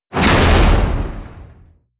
flesh1.wav